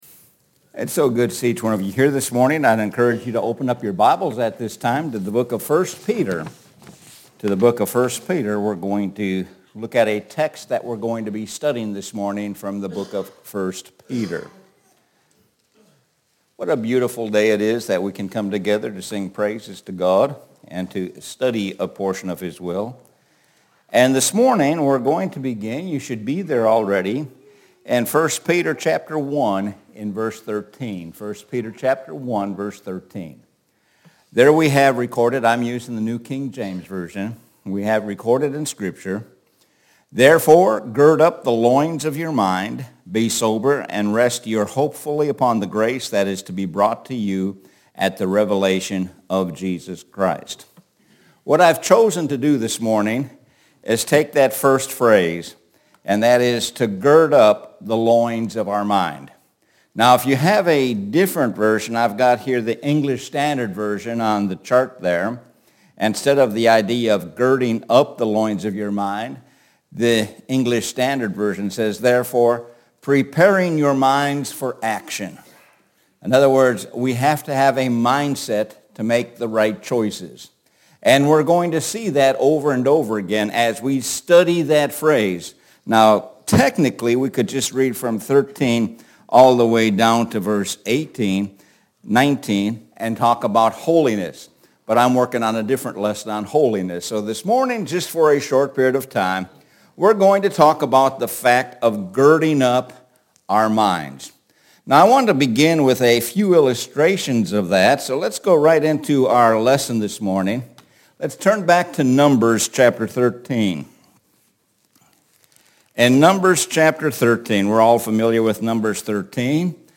Sun AM Sermon – Gird up your loins – 03.03.24